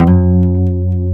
G 2 HAMRNYL.wav